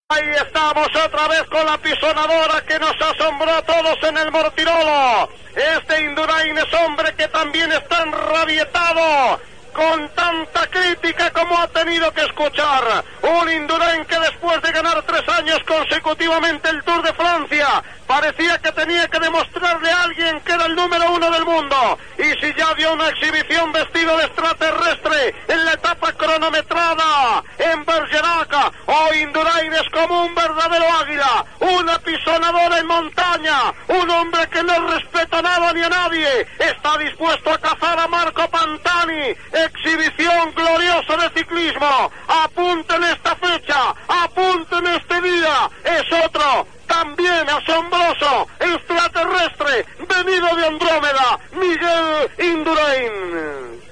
Narració de l'onzena etapa del Tour de França amb arribada als Pirineus, a l'alt del cim de Hautacam. El ciclista Miguel Indurain contacte i supera a Marco Pantani que anava escapat
Esportiu